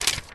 PaperGrab01.wav